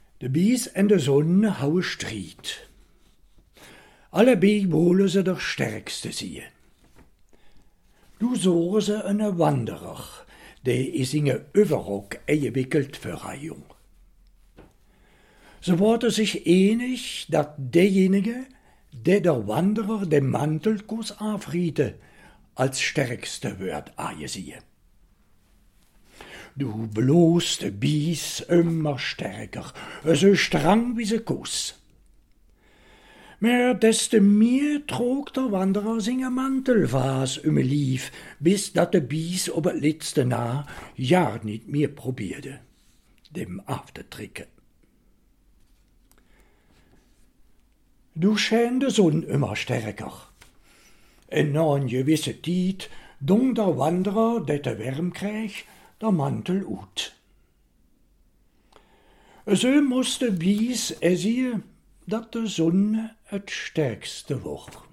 Ciddé, vos ploz schoûter èn eredjistrumint del fåve do vî vî tins «Li bijhe et l' solea» dins l' pårler del Calmene pol Djåzant atlasse éndjolike des lingaedjes di France et d' avår la.